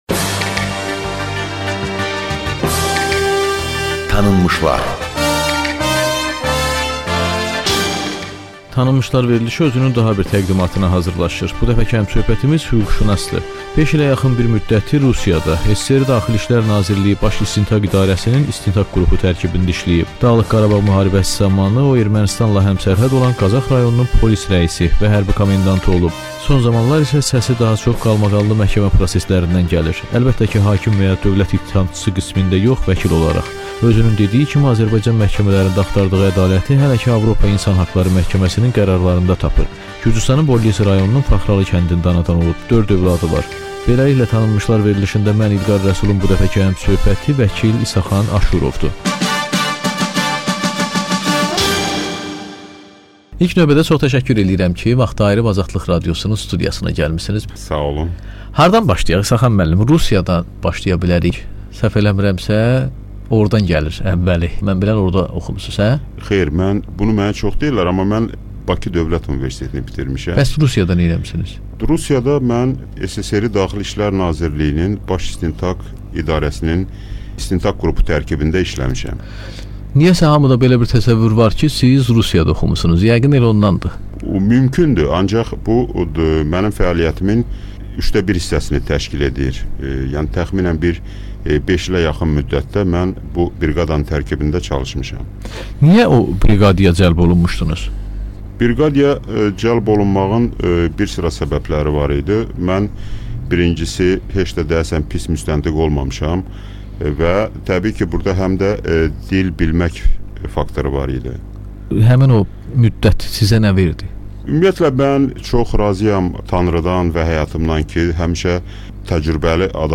müsahibəsi